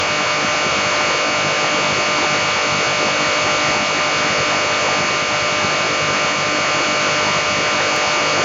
IS-136 voice downlink channel during a call.
IS-136-voice.mp3